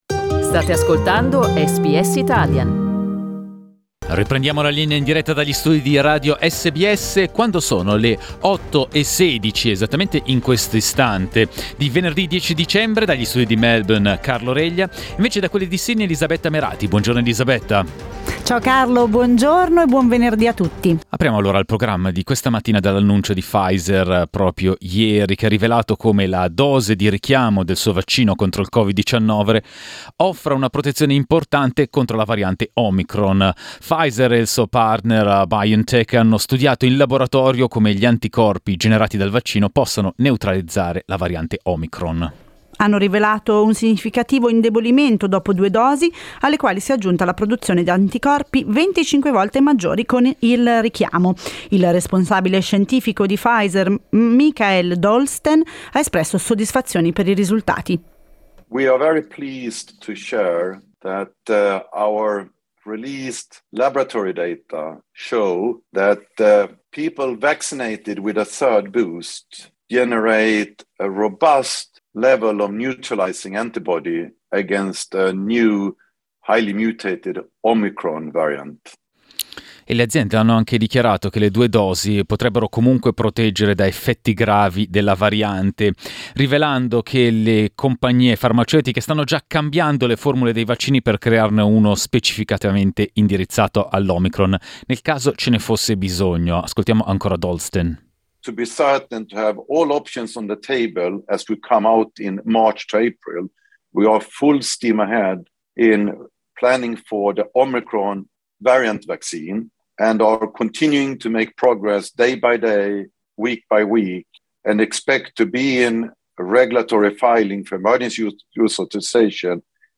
Ascolta i dettagli nel servizio di SBS Italian.